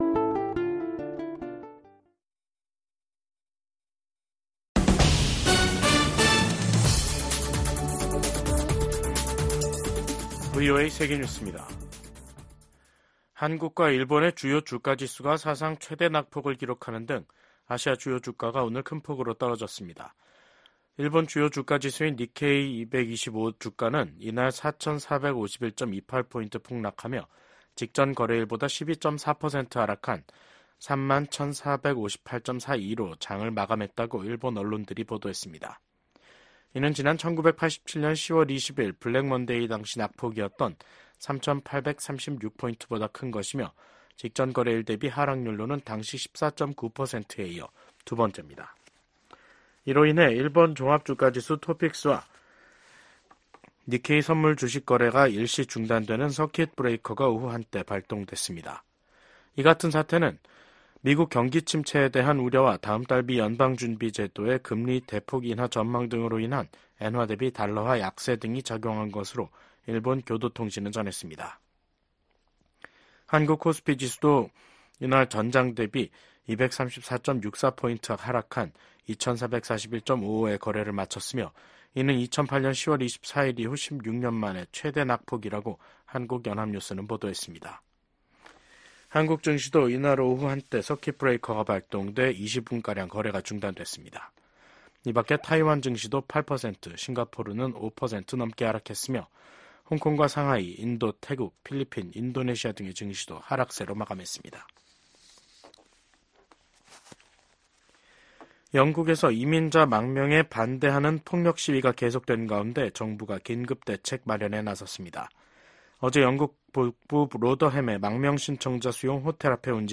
VOA 한국어 간판 뉴스 프로그램 '뉴스 투데이', 2024년 8월 5일 2부 방송입니다. 북한이 핵탄두 장착이 가능한 신형 전술탄도미사일 발사대를 대규모 전방 배치한다고 발표했습니다. 한국 군 당국은 해당 무기체계 성능과 전력화 여부에 대해 추적 중이라고 밝혔습니다. 미국 국방부가 오는 11월 미국 대선을 전후한 북한의 7차 핵실험 가능성과 관련해 계속 주시할 것이라는 입장을 밝혔습니다.